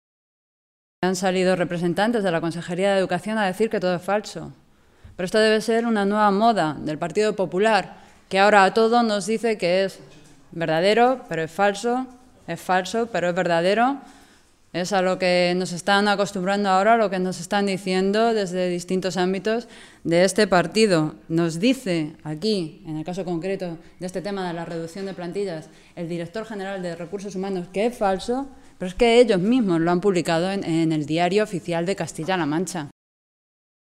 Carmen Rodrigo, portavoz de Educación del Grupo Parlamentario Socialista
Cortes de audio de la rueda de prensa